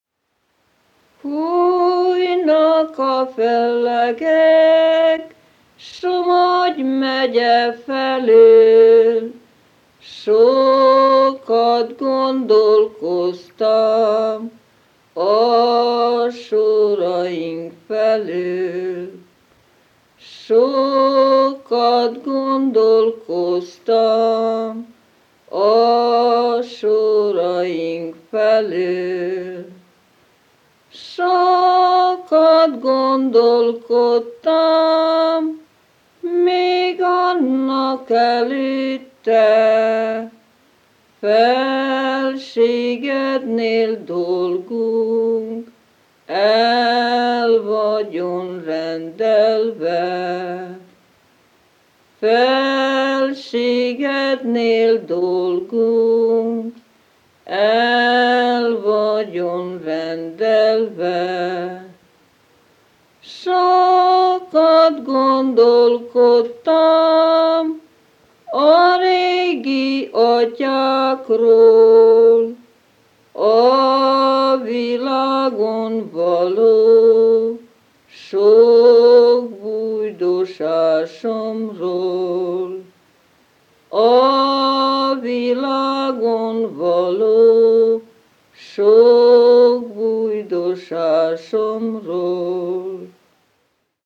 Pour aller plus loin, l’écoute de la musique vocale de la Transdanubie est conseillée et plus particulièrement celle de comté de Somogy : les chants interprétés a capella, les exécutions parlando / rubato, les ornementations, les subtilités des interprétations ainsi que l’intonation de la tierce neutre.
Seconde version, par la même chanteuse, du chant Fùjnak a fellegek :
Enregistré : le 07.03.1962. à Nemespátró – comité de Somogy – Transdanubie (l’ouest de la Hongrie)